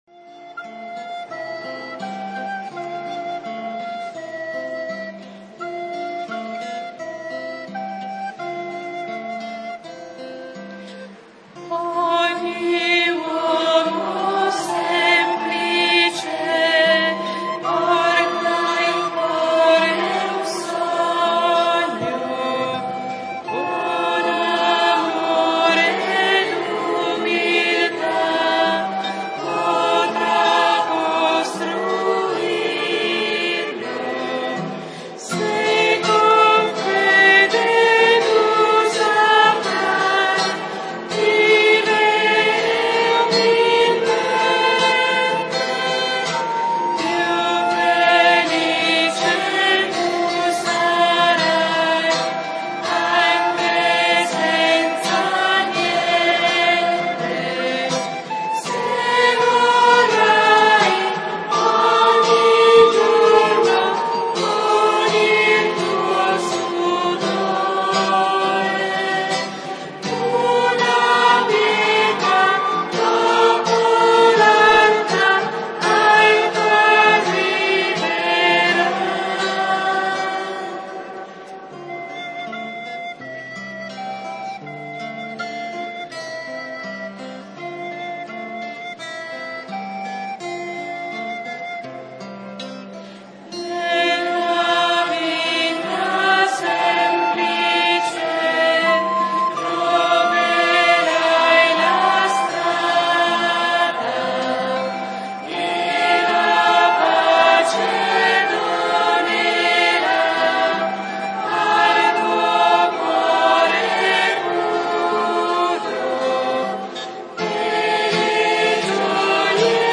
DOMENICA IN ALBIS (Battesimi di Pasqua)
canto: